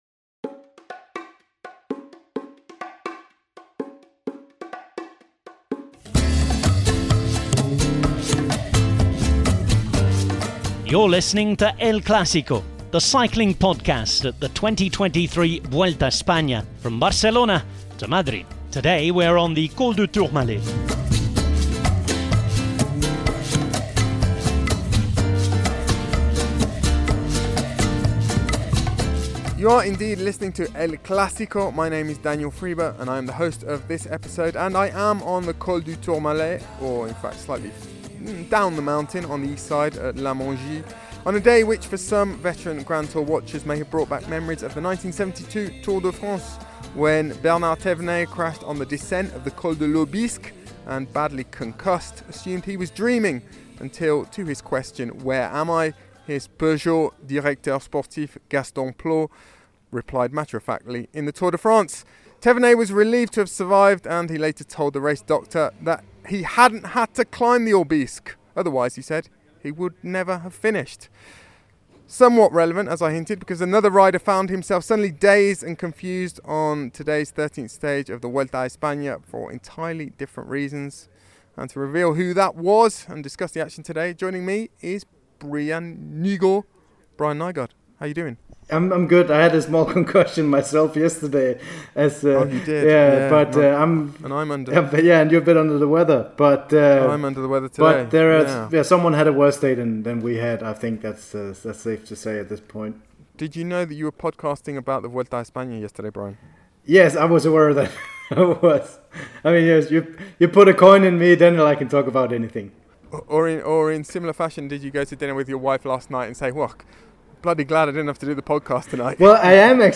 rider interviews